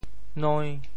noi~5.mp3